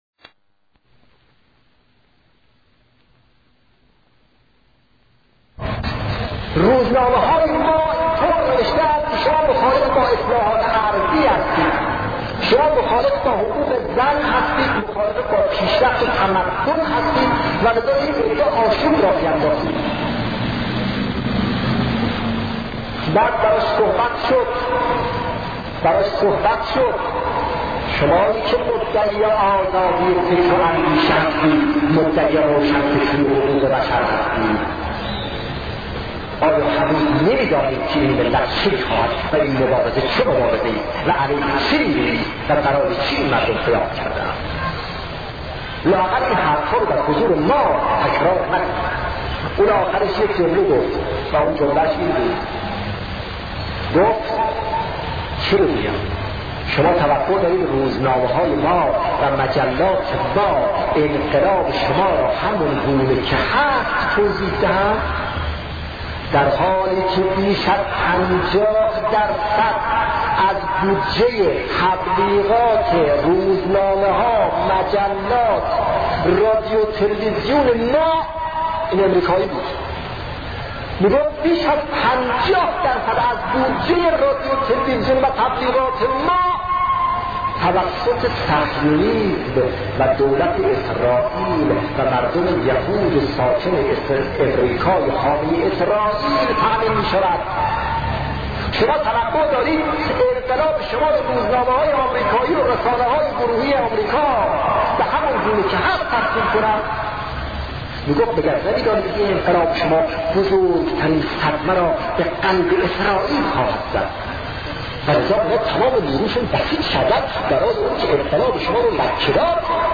صوت سخنرانی شهید محمدجواد باهنر(ره) در خصوص خودسازی-بخش‌دوم